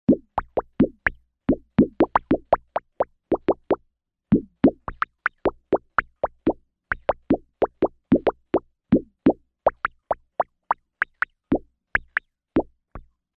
Звуки лопающихся пузырьков
Мелкие пузыри лопают